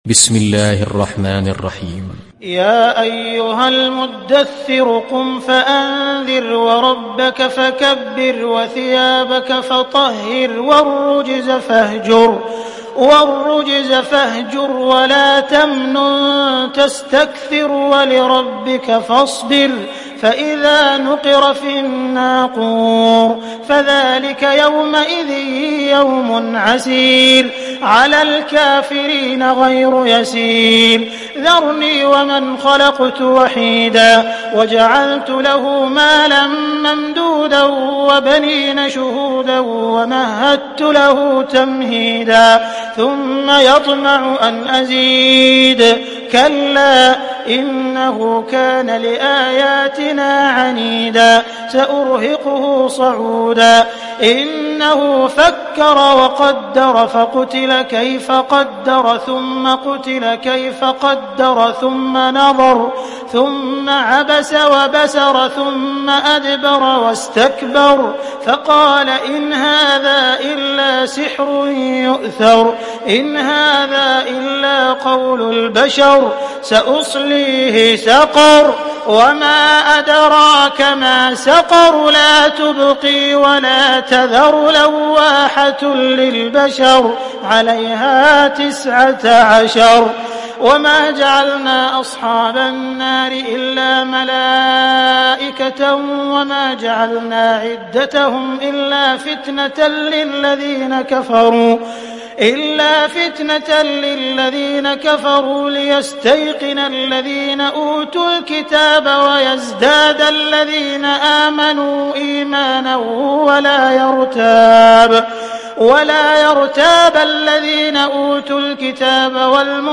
Hafs an Assim